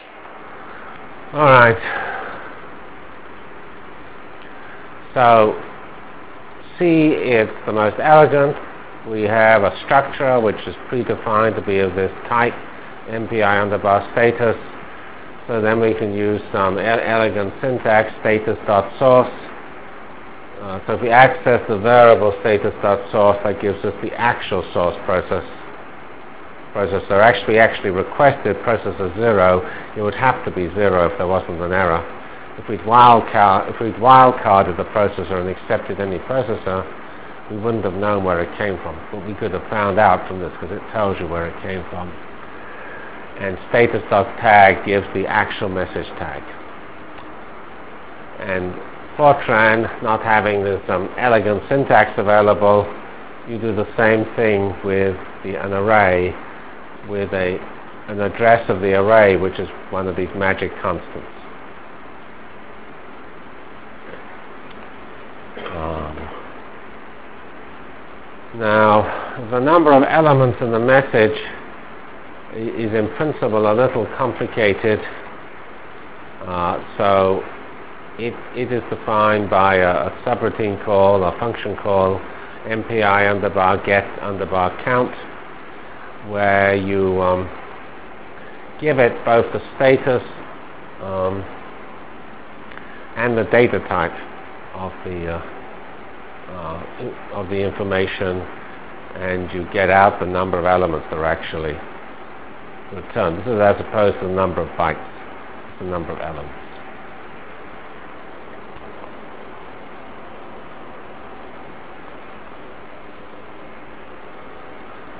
From CPS615-Completion of MPI foilset and Application to Jacobi Iteration in 2D Delivered Lectures of CPS615 Basic Simulation Track for Computational Science -- 7 November 96. by Geoffrey C. Fox